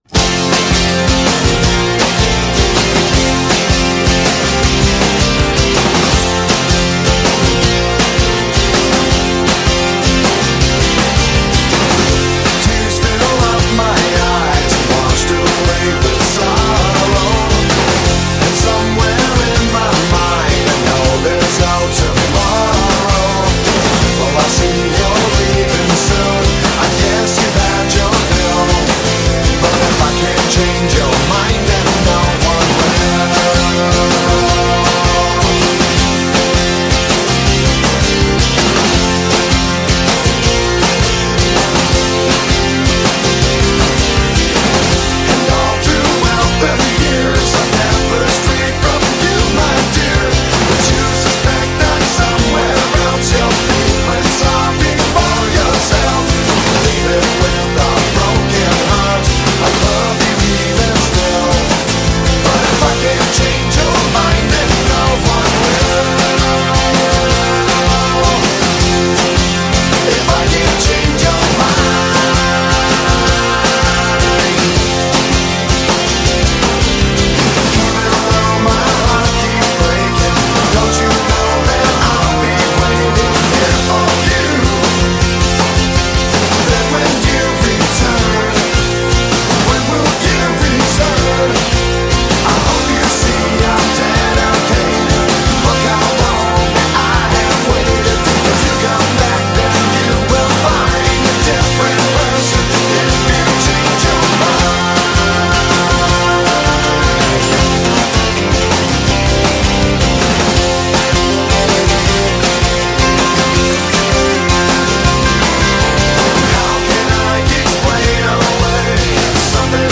alternatieve rock